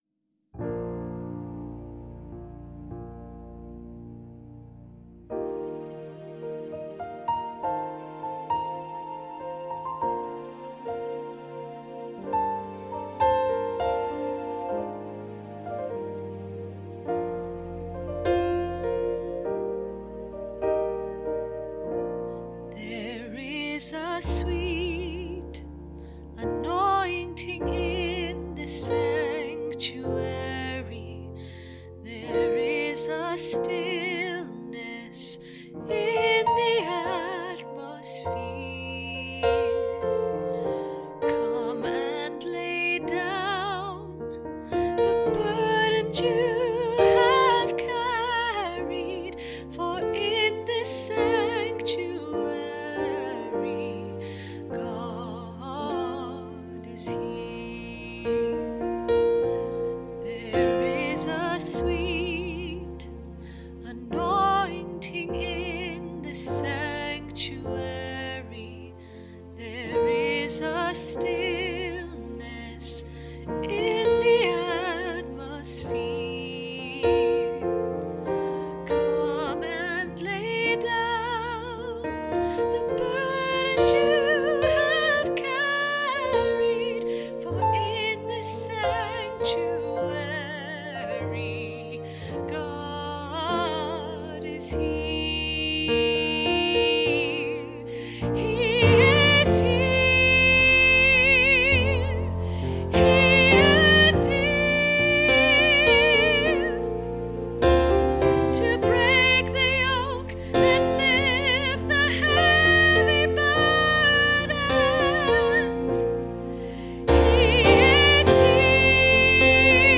God is Here Soprano MP3 - Three Valleys Gospel Choir
God is Here Soprano MP3